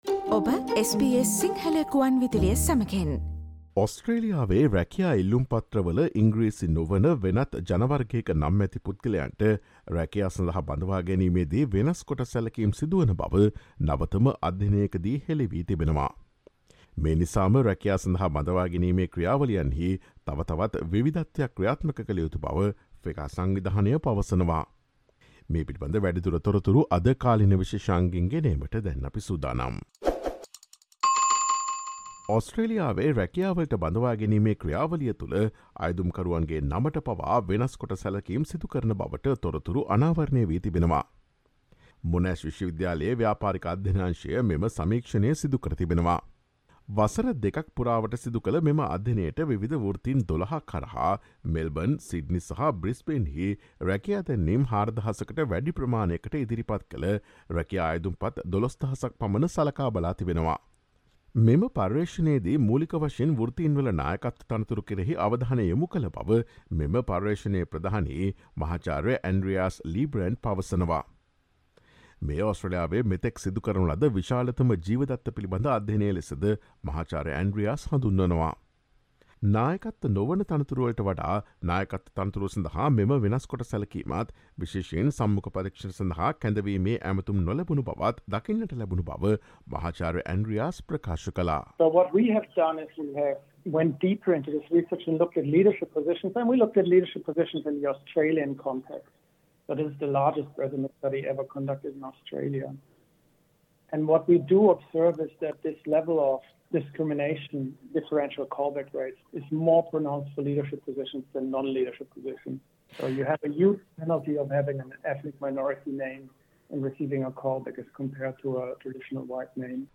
A new study indicates that English sounding names outperform ethnic names in job applications. Listen to the SBS Sinhala radio current affair feature on this issue.